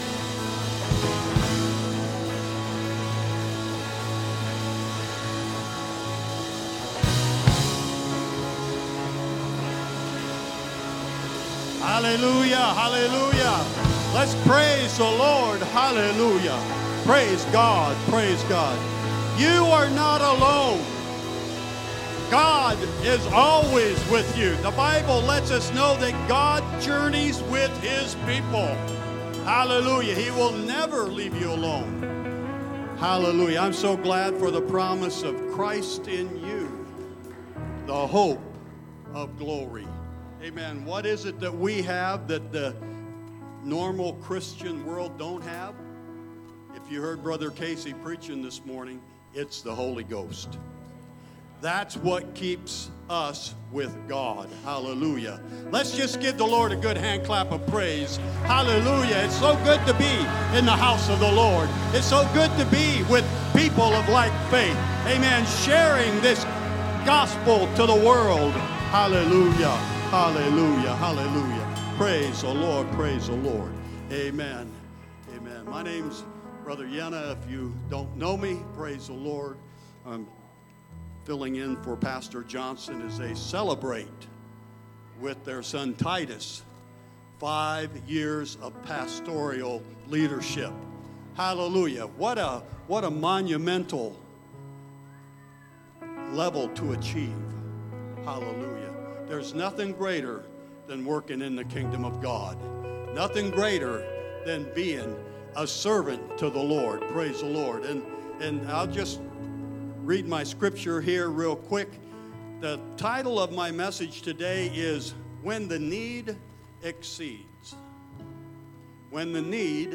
Sermons | Elkhart Life Church
Sunday Service When the Need Exceeds